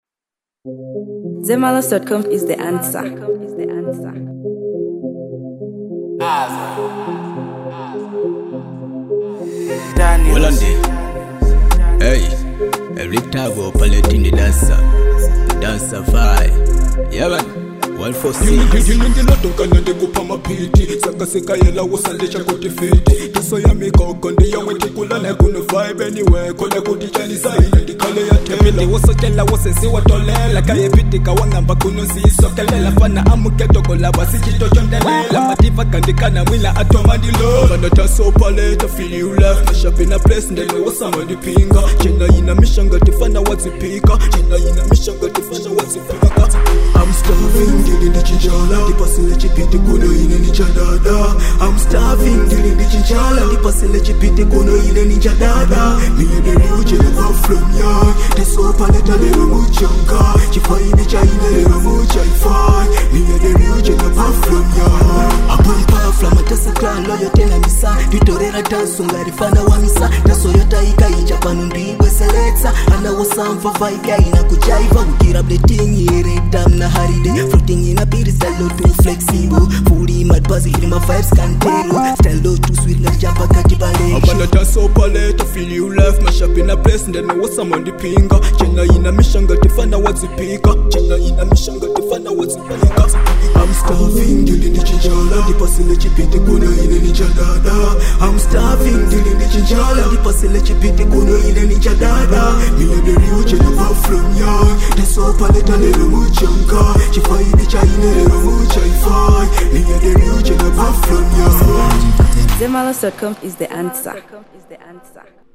Genre: Dancehall.